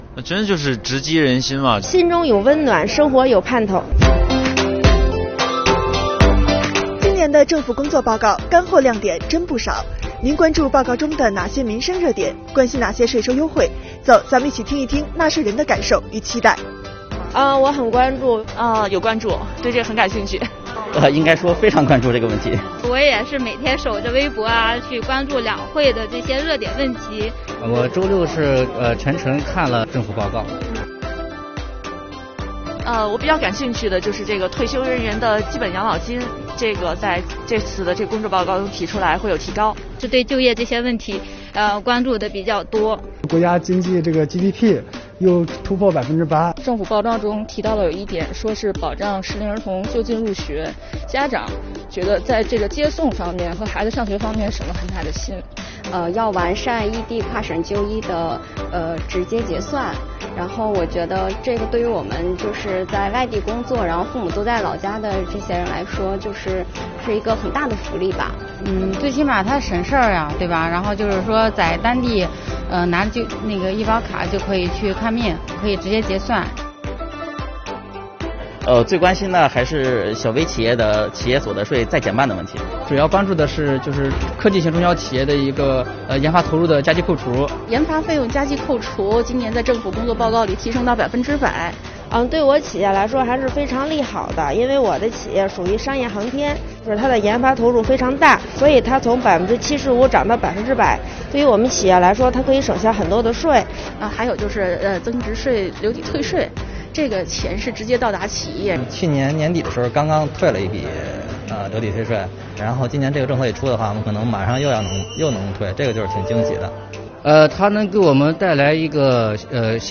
标题: 街采 | 政府工作报告，听！他们这样说